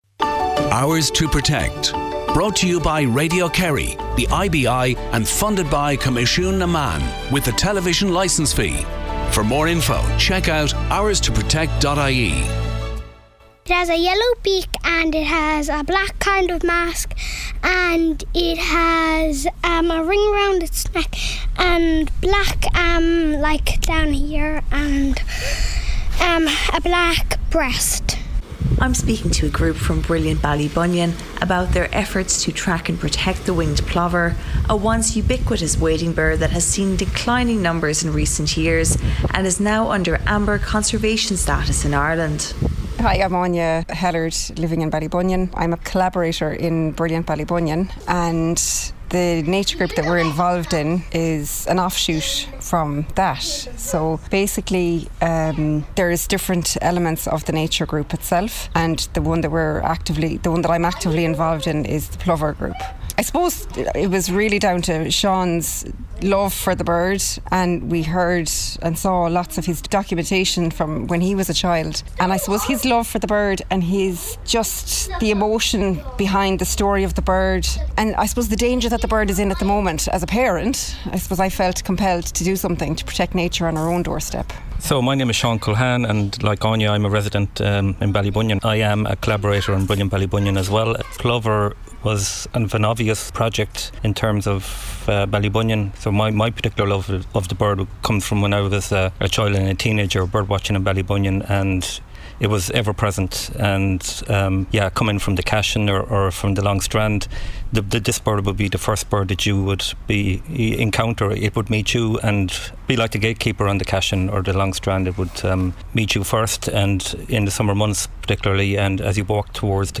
Radio Kerry - August Broadcast 1 - Protecting the Ringed Plover - OursToProtect